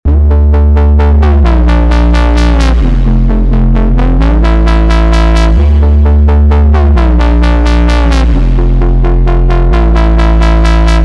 drum & bass samples
Bass Saw 7 Dmin
Bass-Saw-7-Dmin.mp3